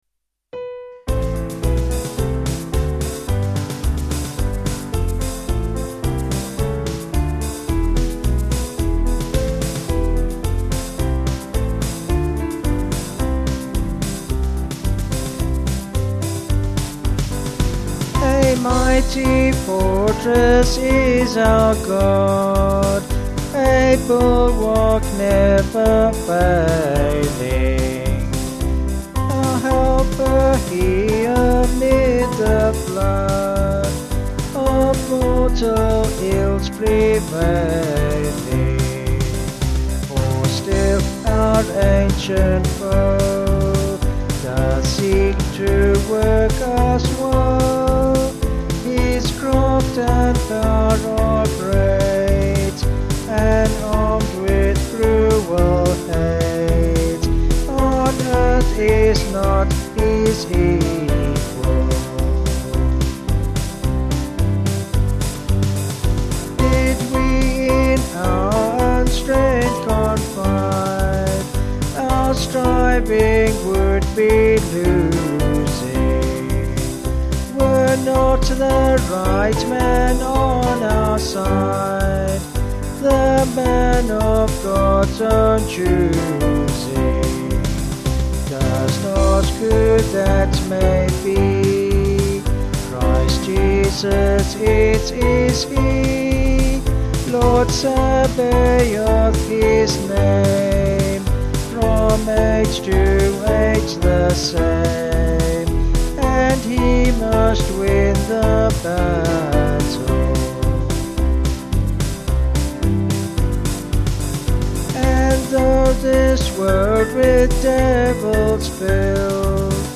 4/C-Db
Vocals and Band   264.2kb Sung Lyrics